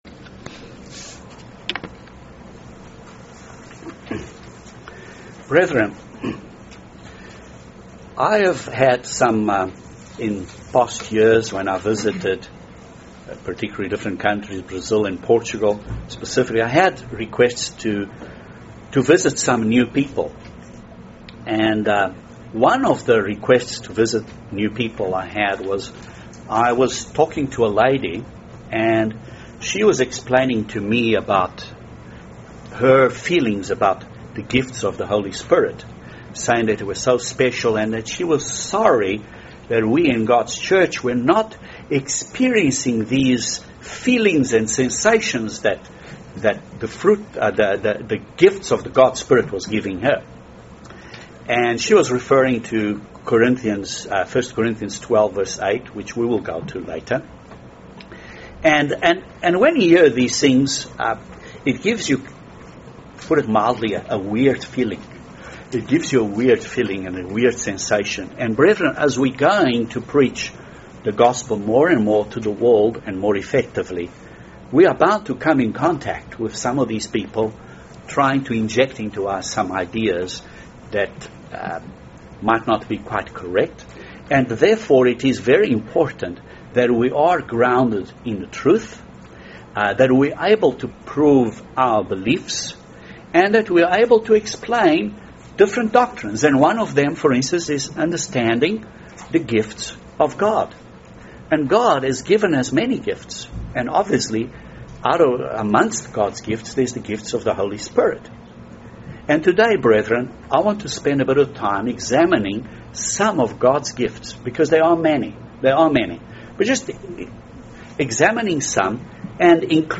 This sermon reviews some of these gifts of God from the scriptures.